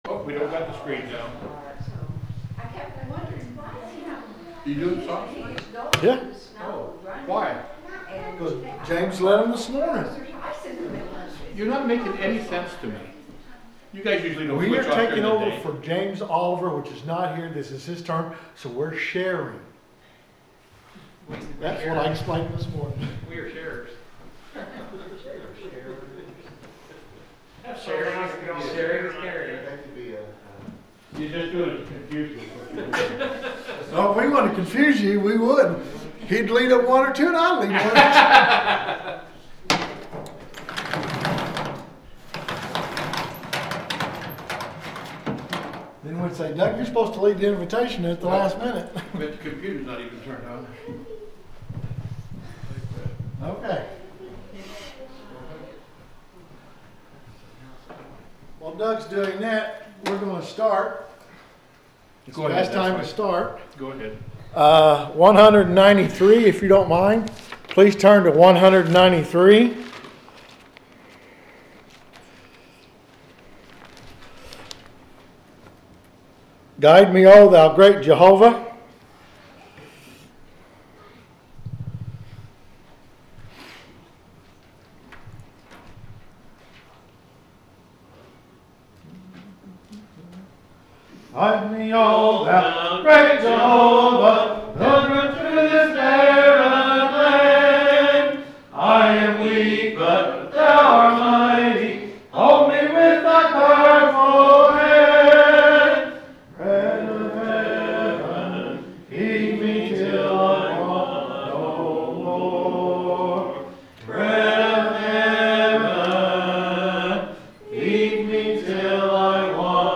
The sermon is from our live stream on 2/1/2026